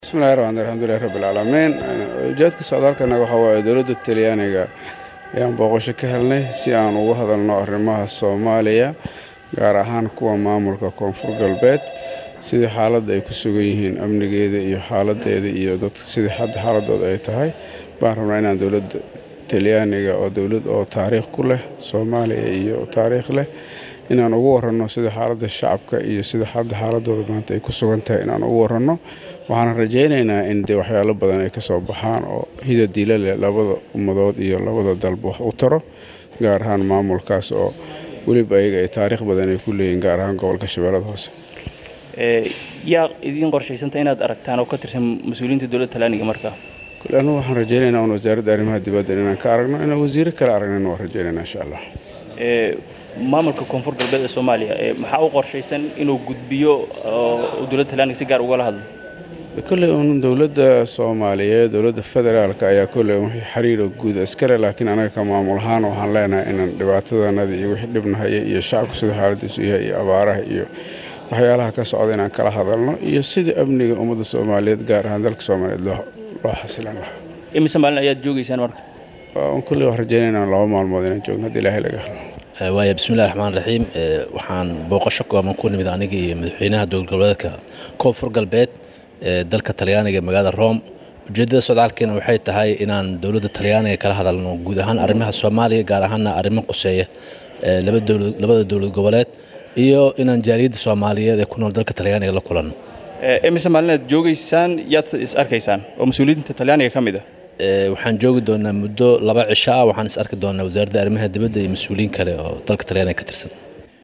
Madaxweyneyaasha Koofur galbeed Soomaaliya iyo Galmudug ayaa warbaahinta ugu warbixiyay shirka ay ugu qeyb galaya Talyaaniga gaar ahaan Magaalada Roma.